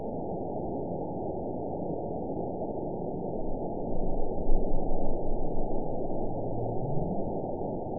event 912362 date 03/25/22 time 10:01:36 GMT (3 years, 1 month ago) score 9.60 location TSS-AB01 detected by nrw target species NRW annotations +NRW Spectrogram: Frequency (kHz) vs. Time (s) audio not available .wav